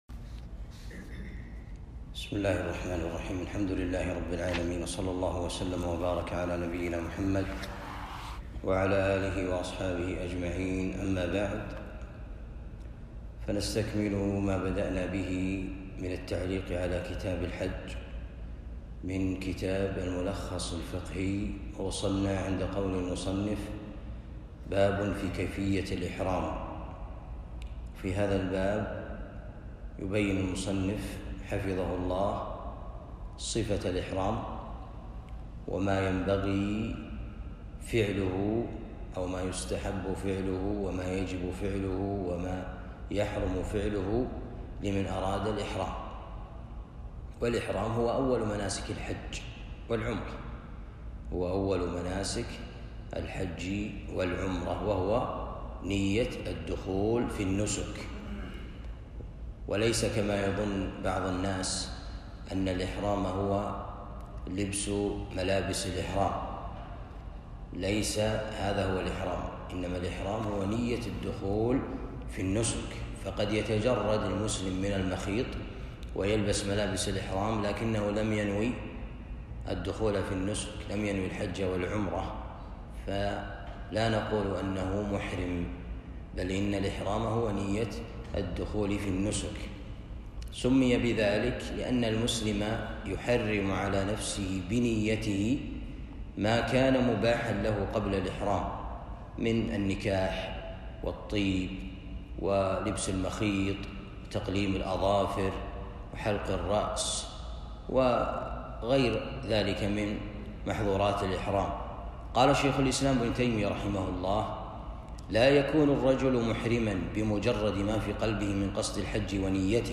الدرس الرابع من كتاب الحج باب في كيفية الإحرام